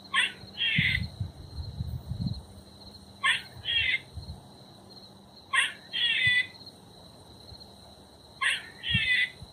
The mating call of a female Grey squirrel.
grey_squirrel_mating_call.mp3